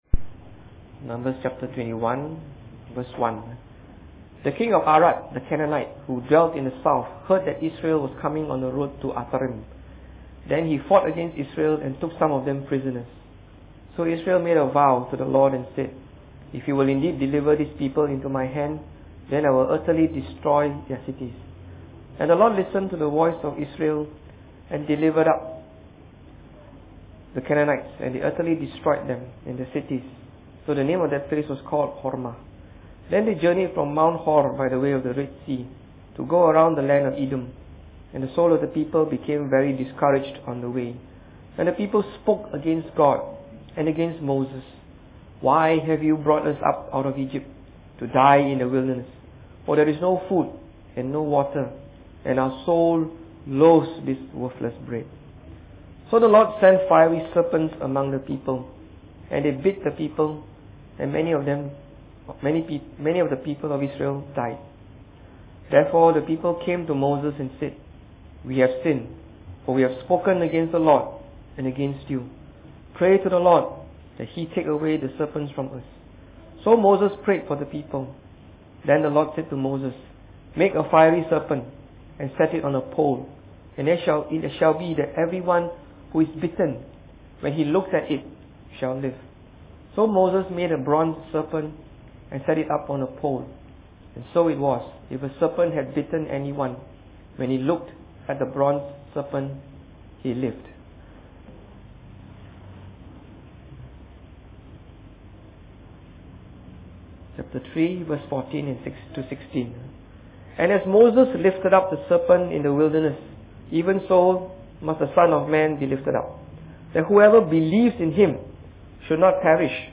Preached on the 24th of February 2013.